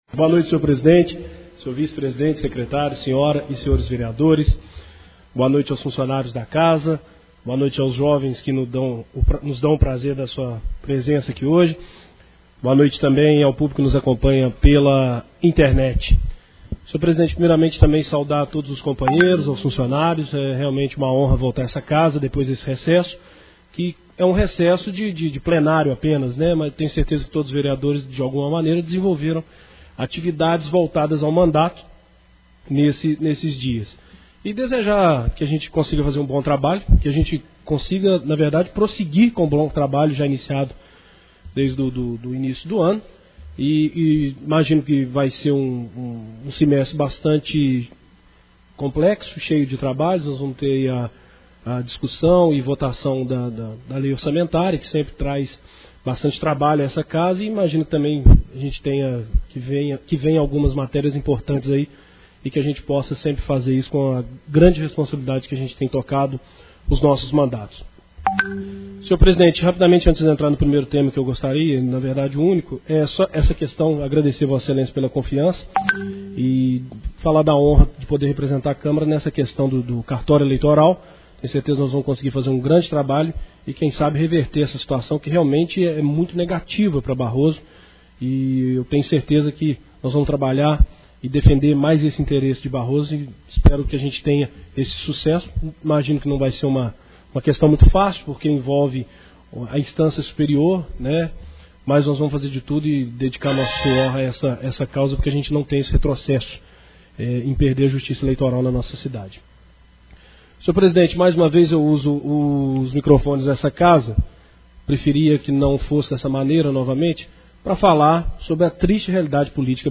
Confira o áudio de cada vereador na reunião dessa quinta-feira. Eles falam sobre a volta das reuniões ordinárias e sobre a posição dos deputados federais.
EDUARDO PINTO (PV) – Foi o primeiro a falar e anunciou que não apoiará mais o candidato Luiz Fernando (PP).